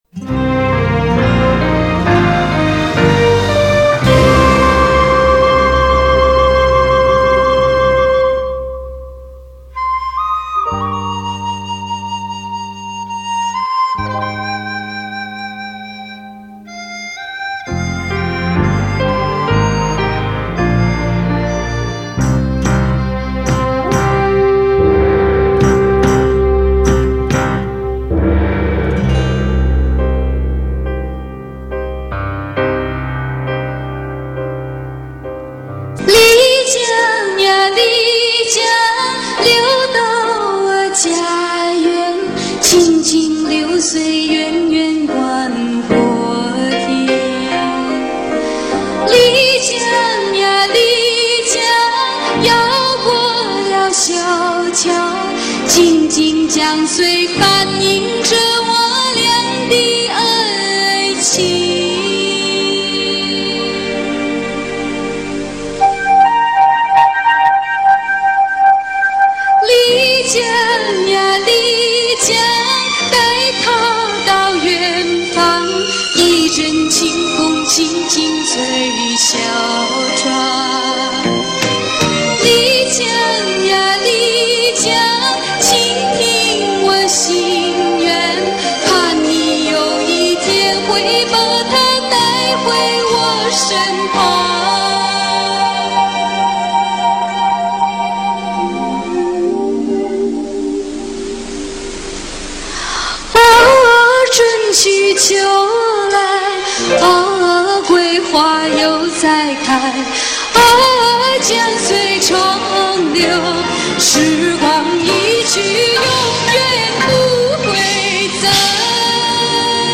插曲